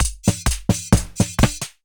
erase_machinemechanic_130.mp3